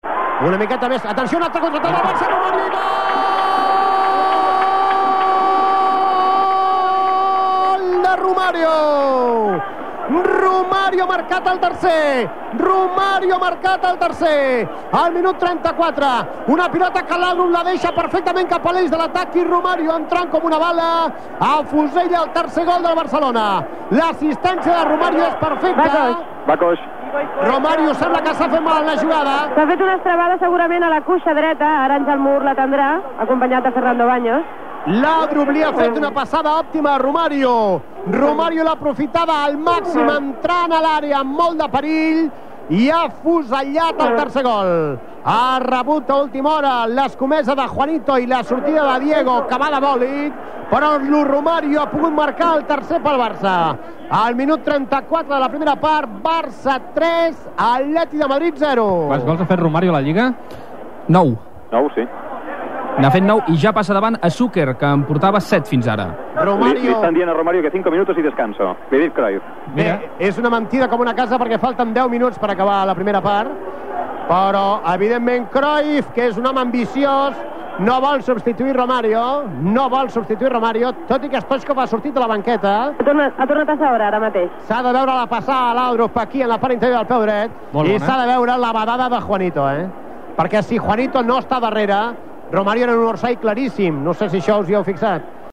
Narració del tercer gol de Romario, aconseguit en el partit Atlético de Madrid-Futbol Club Barcelona en el minut 34.
Esportiu
FM